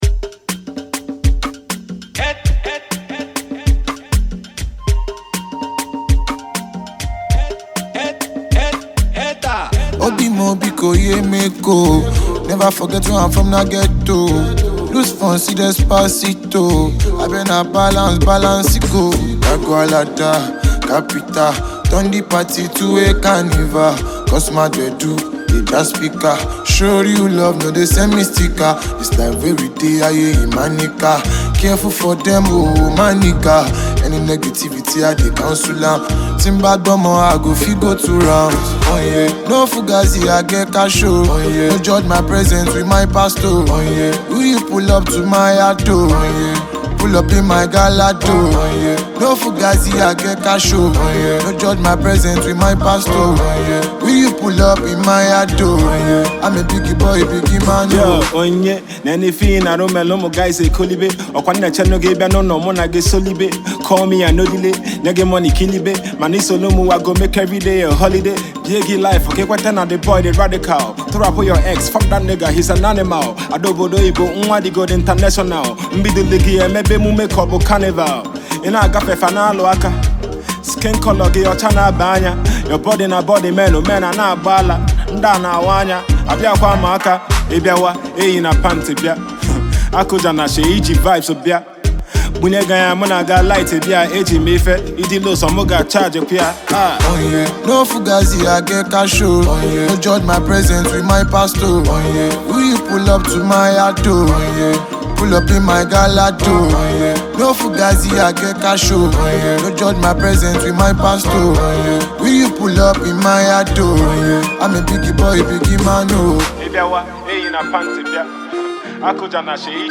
Nigerian singer and songwriter